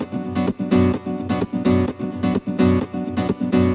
VEH3 Nylon Guitar Kit 2 - 6 B min.wav